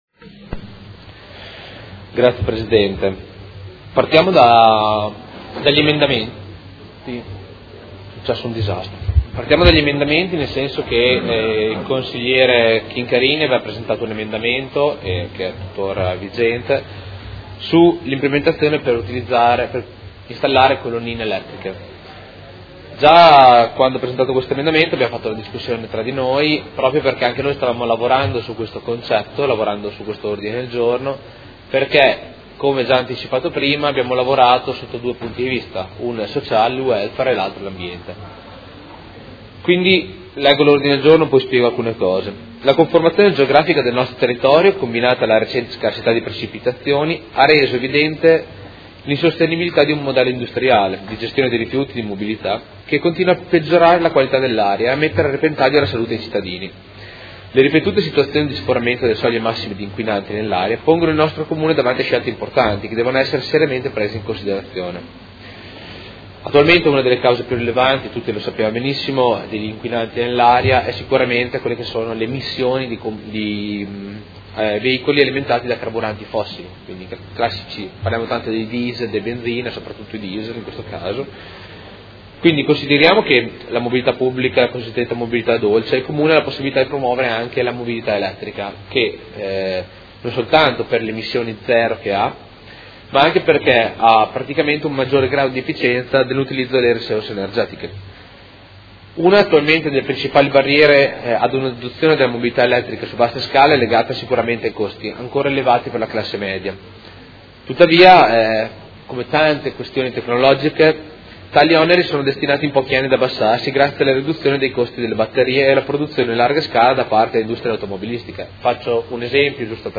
Seduta del 25 febbraio. Approvazione Bilancio: presentazione odg Prot. 27038